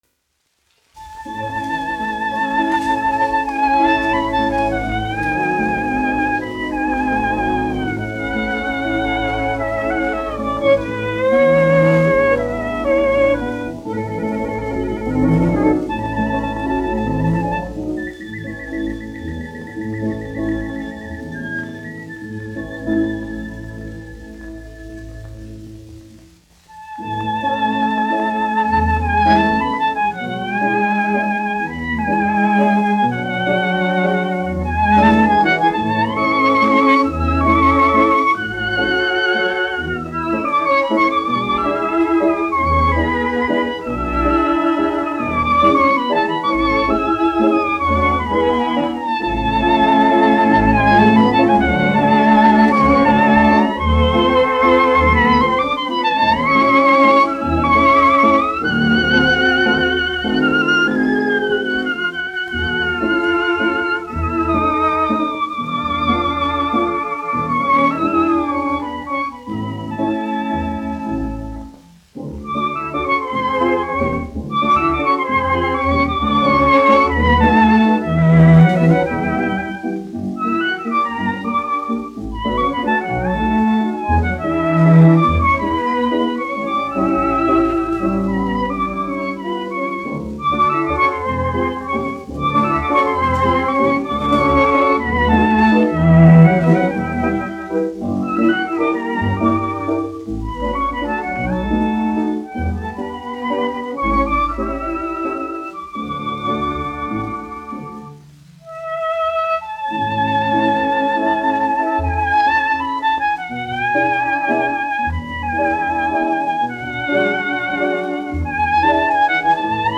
1 skpl. : analogs, 78 apgr/min, mono ; 25 cm
Orķestra mūzika, aranžējumi
Latvijas vēsturiskie šellaka skaņuplašu ieraksti (Kolekcija)